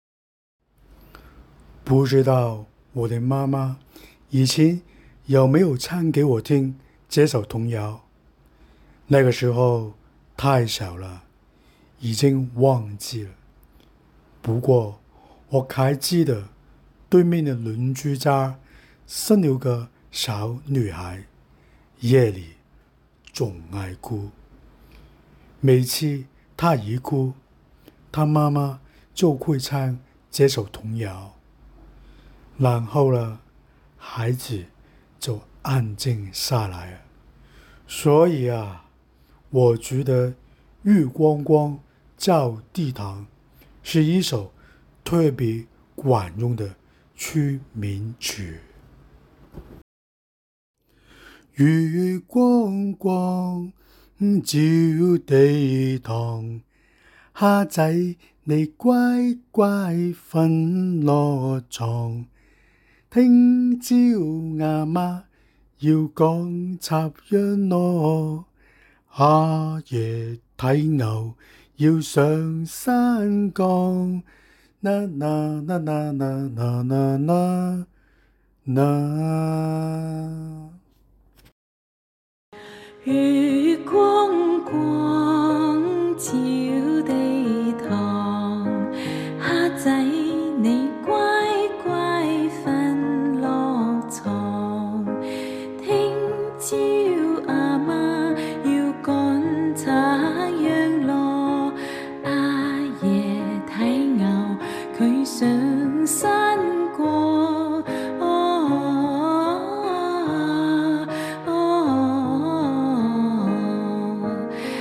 那些年的童謠曲
这首童谣不仅是孩子们的睡前摇篮曲，也是成年人怀旧思乡的寄托。